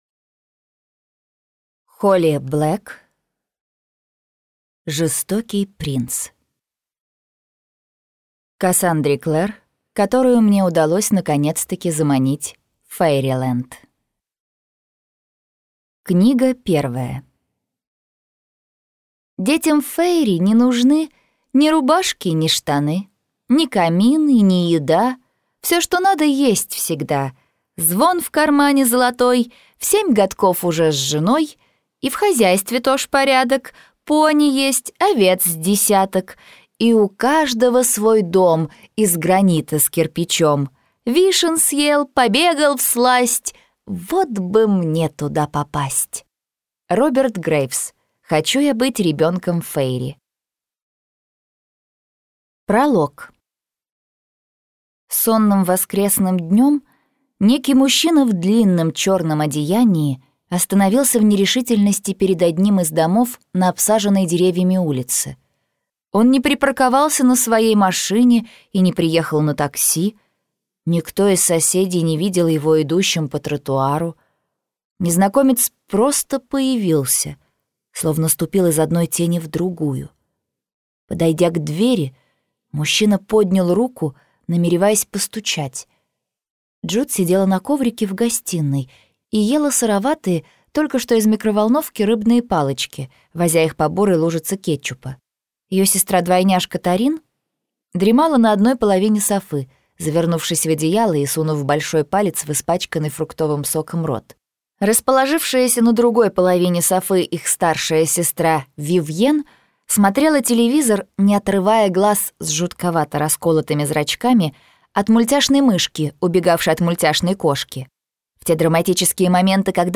Аудиокнига Жестокий принц - купить, скачать и слушать онлайн | КнигоПоиск